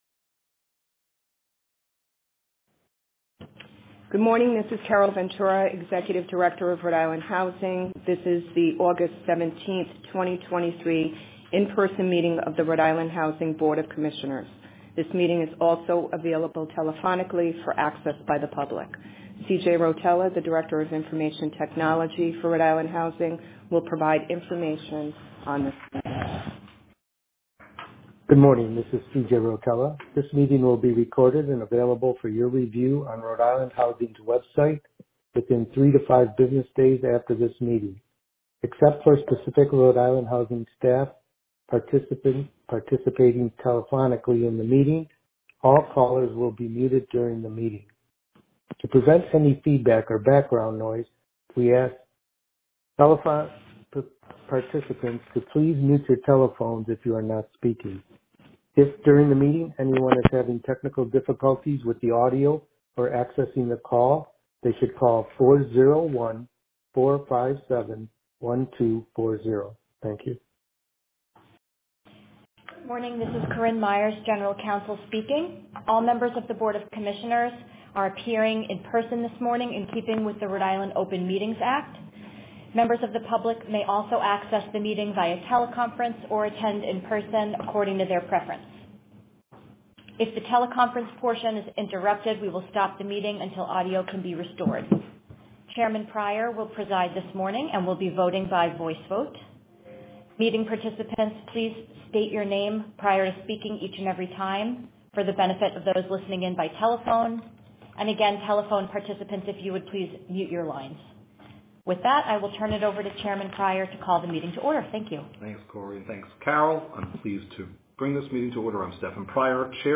Recording of RIHousing Board of Commissioners Meeting: 08.17.2023